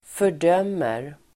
Uttal: [för_d'öm:er]